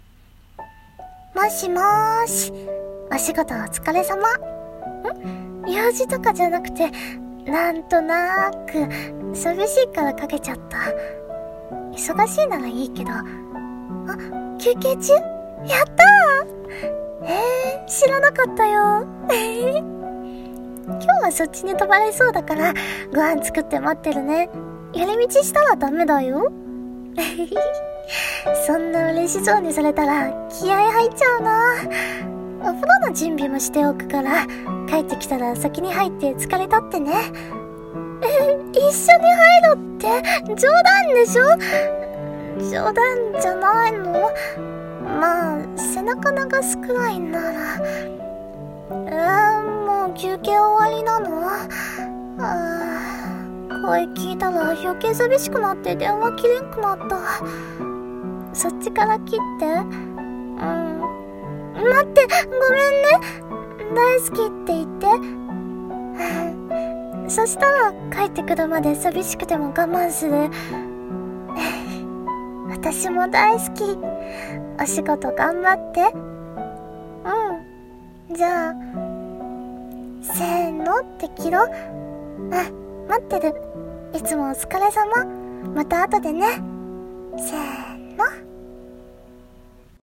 [声劇]嬉しい電話【１人声劇】